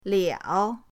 liao3.mp3